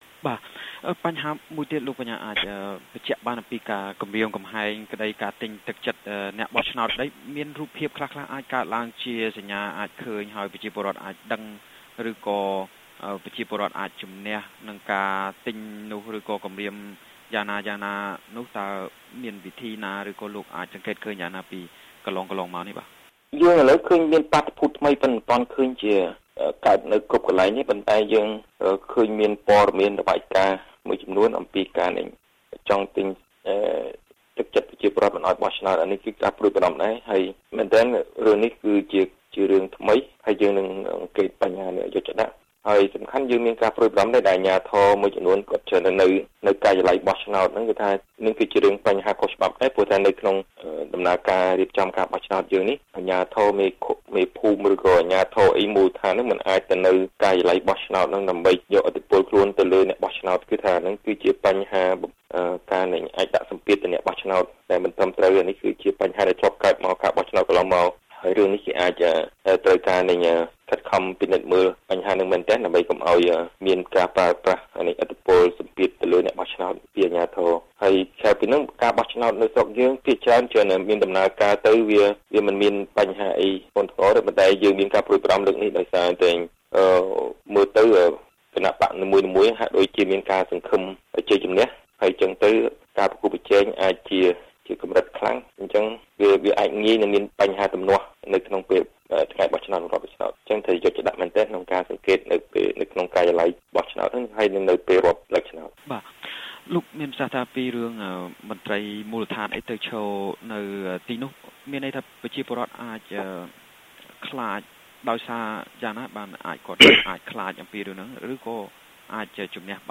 បទសម្ភាសន៍៖ អ្នកសង្កេតការណ៍ណែនាំពីការប្រយ័ត្នចំពោះភាពមិនប្រក្រតីក្នុងការបោះឆ្នោត